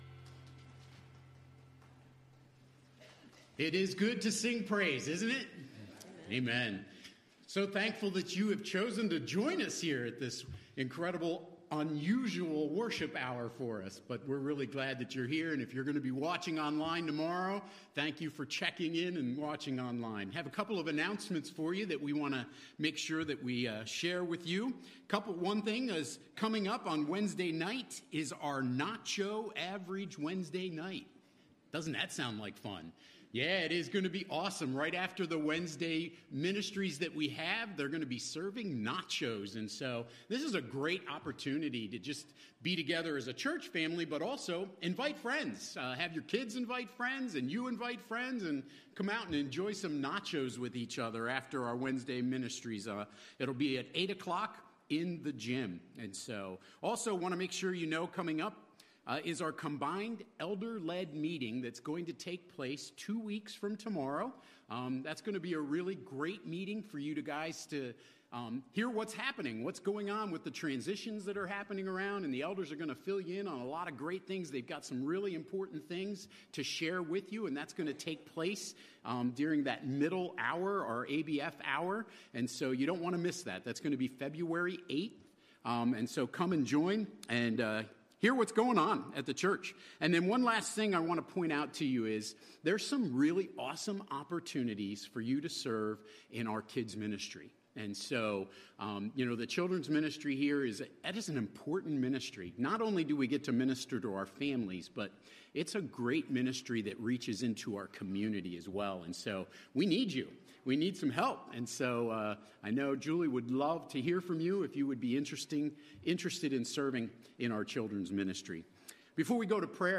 Sermons | Christian Fellowship Church